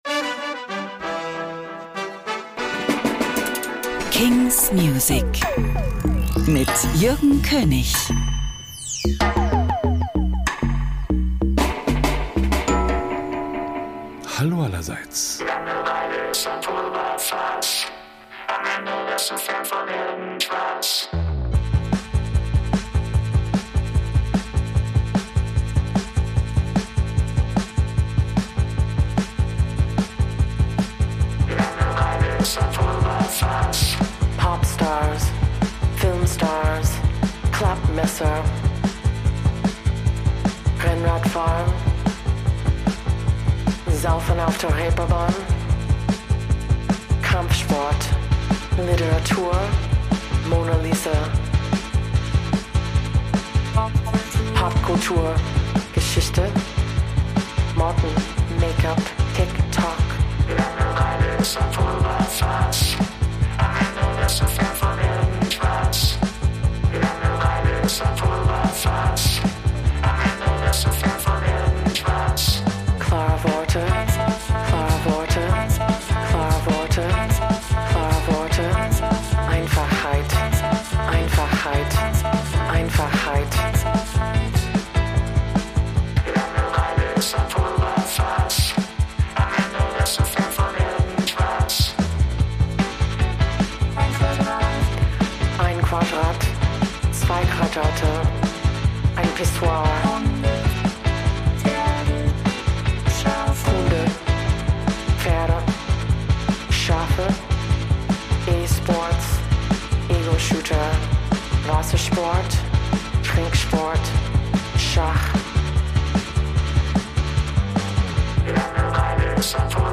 brandnew indie & alternative releases